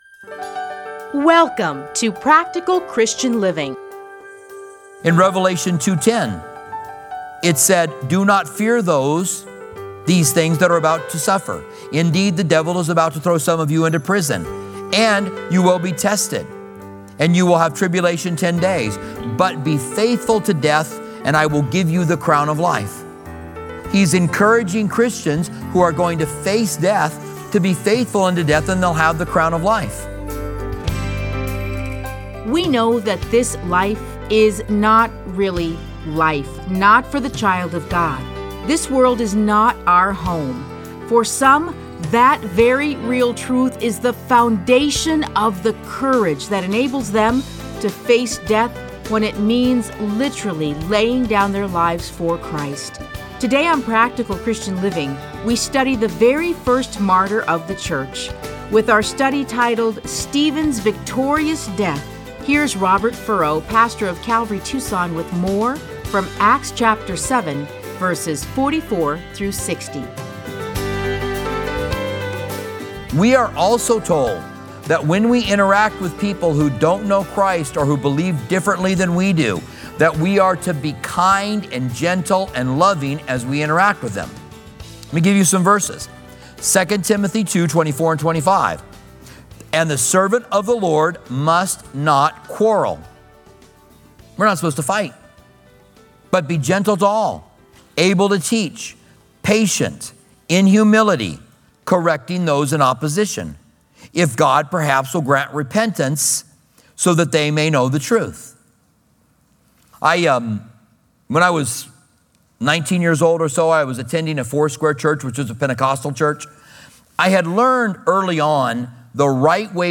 Listen to a teaching from Acts 7:44-60.